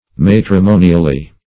matrimonially - definition of matrimonially - synonyms, pronunciation, spelling from Free Dictionary Search Result for " matrimonially" : The Collaborative International Dictionary of English v.0.48: Matrimonially \Mat`ri*mo"ni*al*ly\, adv.
matrimonially.mp3